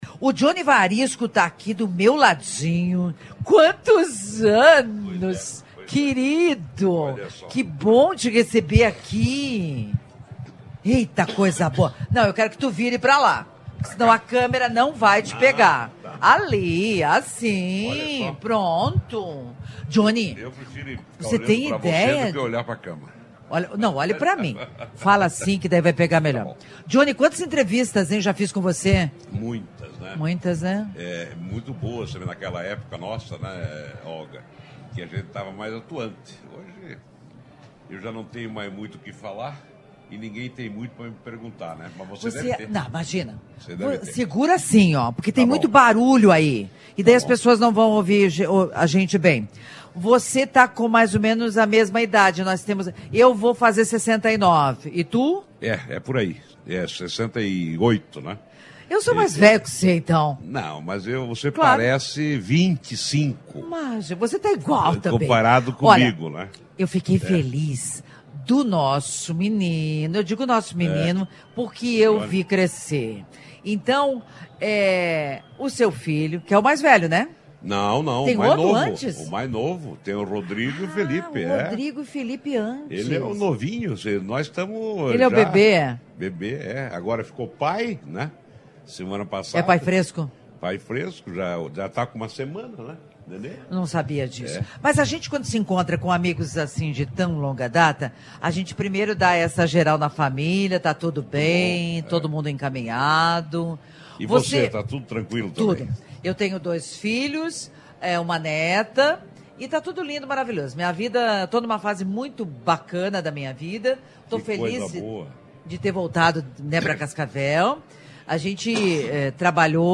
Joni Varisco, ex-deputado federal, também foi um dos maiores produtores de grãos do Brasil. Joni em entrevista à CBN falou um pouco sobre a história de Cascavel.